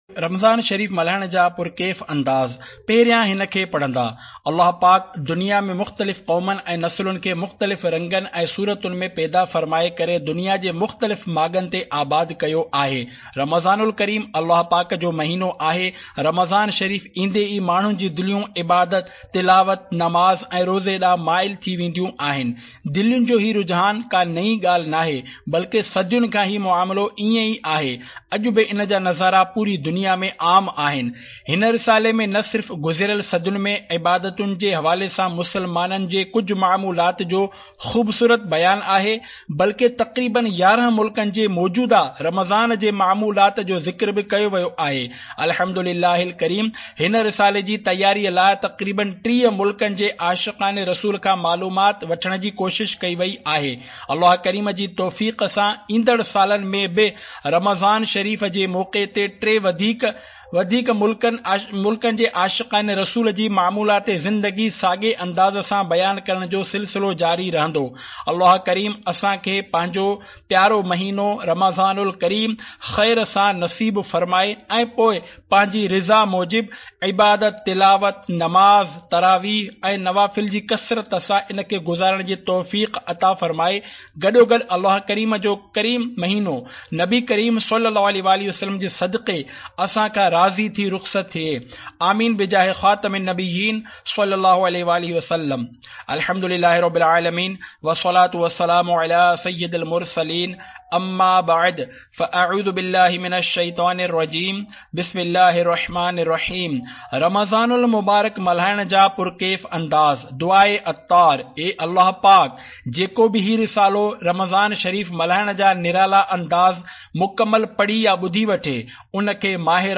Audiobook - Ramazan ul Kareem Manane Ke Pur Kaif Andaz (Sindhi)